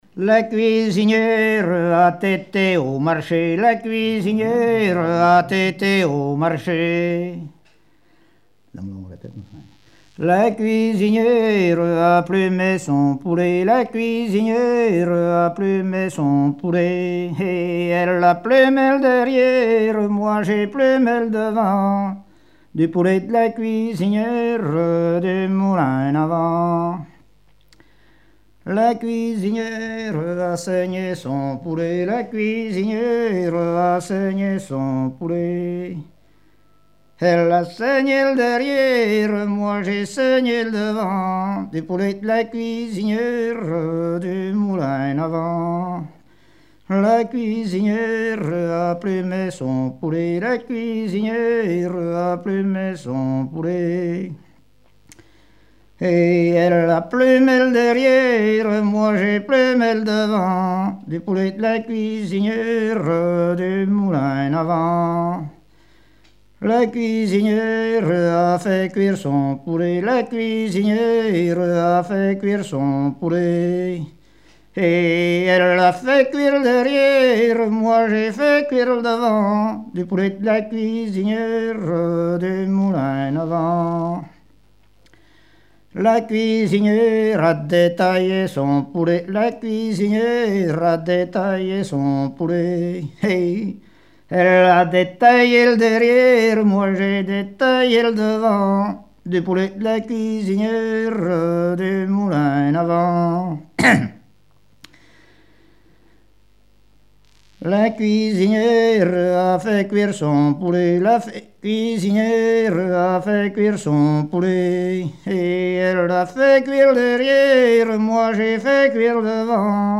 Genre énumérative
Répertoire de chansons et témoignages
Pièce musicale inédite